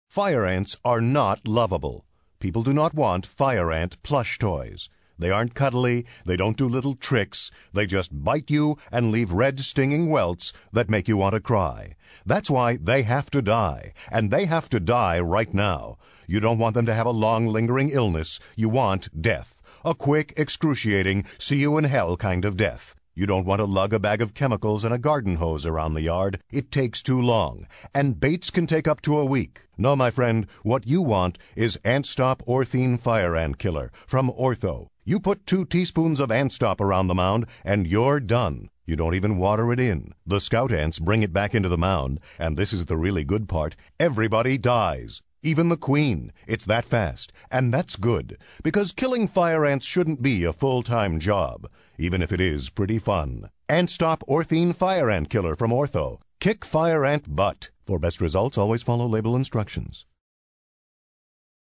With the right script and the right voice, nothing else is needed to capture and hold our attention, as illustrated by this award-winning spot for Ortho’s Fire-Ant Killer, delivered by killer voice actor